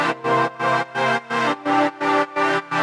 Index of /musicradar/sidechained-samples/170bpm
GnS_Pad-MiscB1:4_170-C.wav